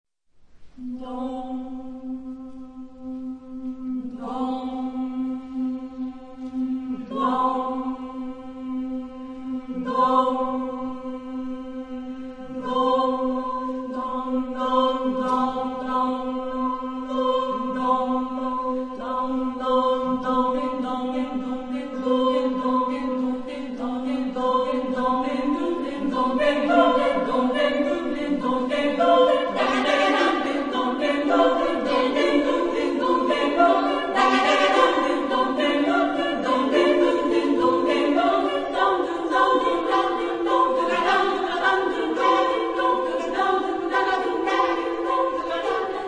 Texto en: fonemas
Género/Estilo/Forma: Profano ; Coro ; Variedades
Tonalidad : pentatónico